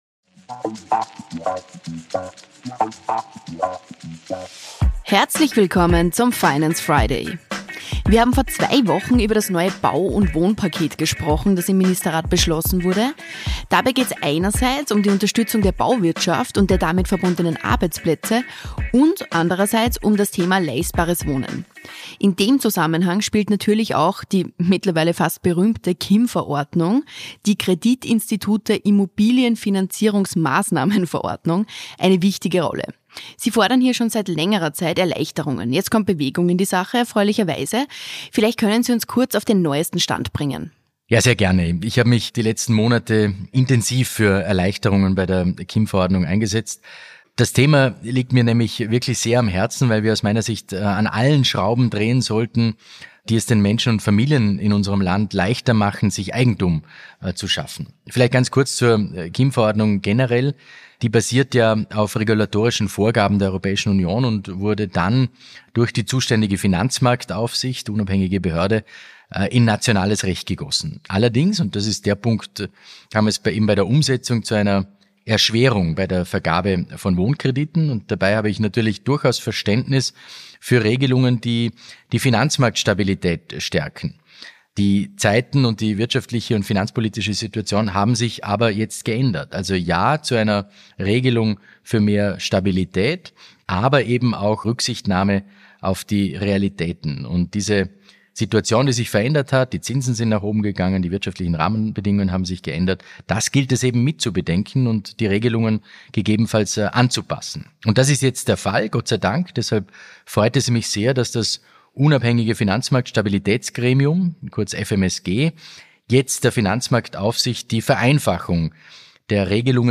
Finanzminister Magnus Brunner spricht